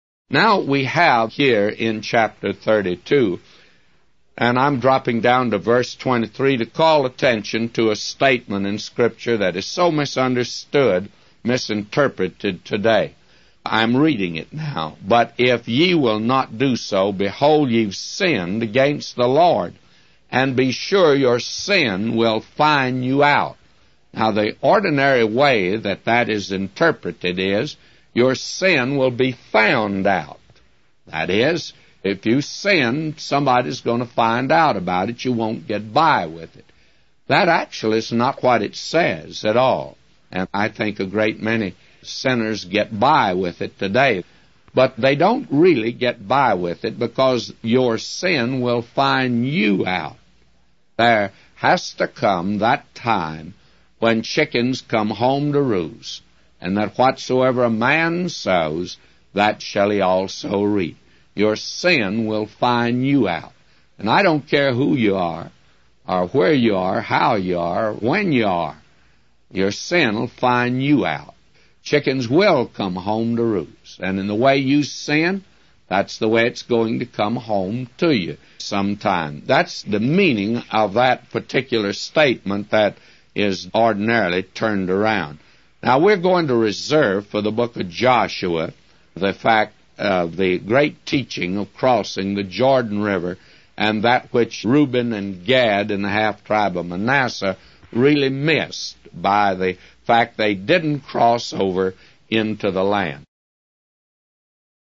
A Commentary By J Vernon MCgee For Numbers 32:20-999